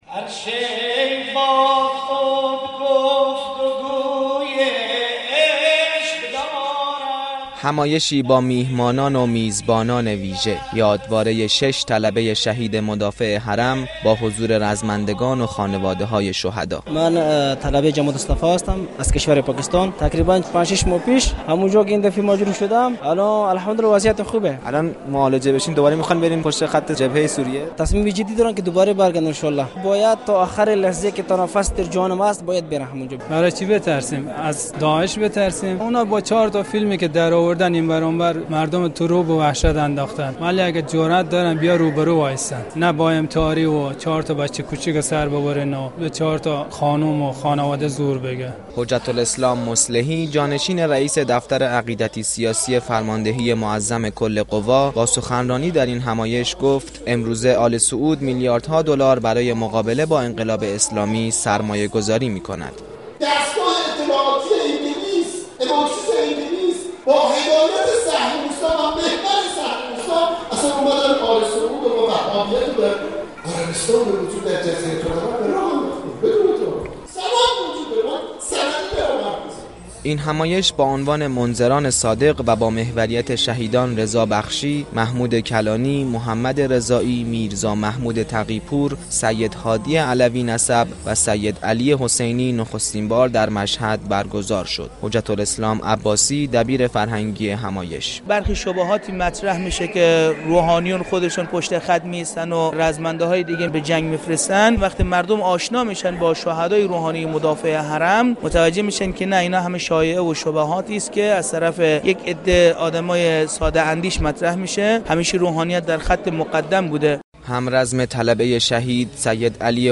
منذران صادق عنوان نخستین یادواره شهدای روحانی مدافع حرم مشهد مقدس بود كه در تالار قدس كتابخانه مركزی آستان قدس رضوی برگزار شد . سخنرانی ، اجرای نغمه های آیینی در رثای شهدای مدافع حرم و تجلیل از خانواده شهدا از برنامه های این مراسم بود .